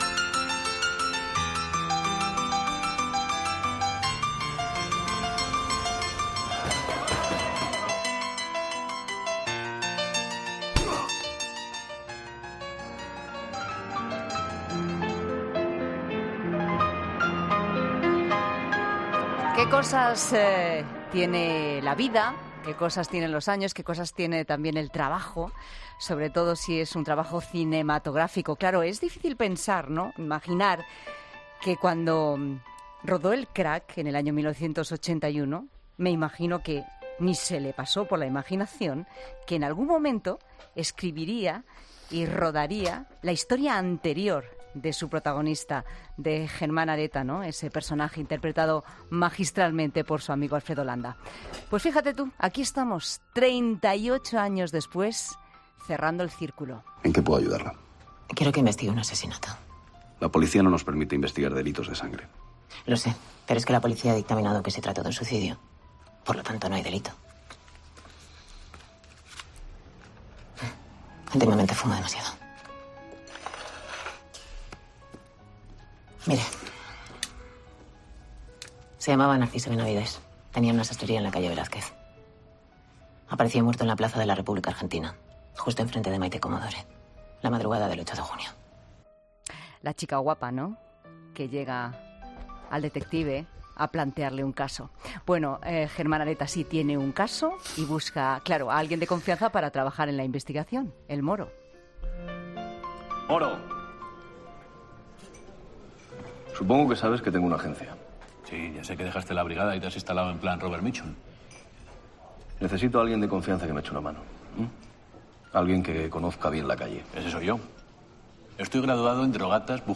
ESCUCHA LA ENTREVISTA A JOSÉ LUIS GARCI Y CARLOS SANTOS Lo anterior, el rodaje, lo han contado en La Tarde de COPE José Luis Garci , el director, y uno de los actores, Carlos Santos.